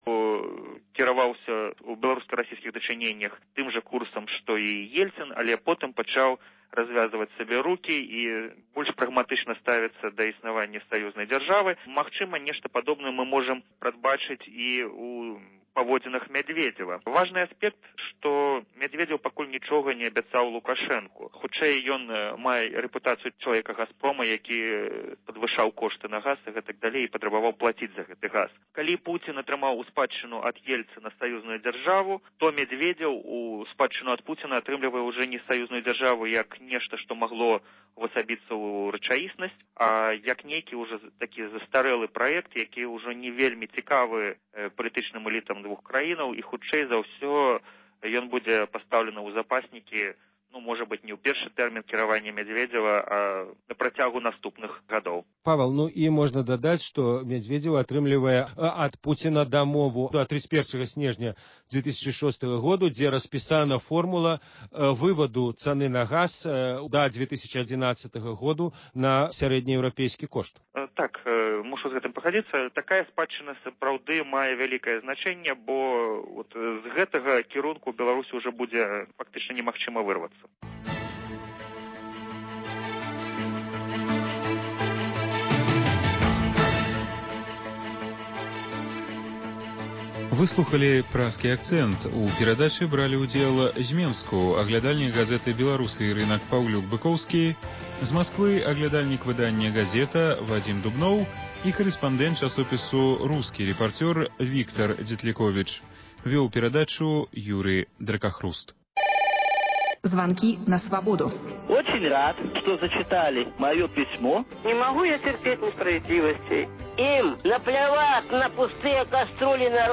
У аглядзе тэлефанаваньняў слухачы камэнтуюць падзеі тыдня.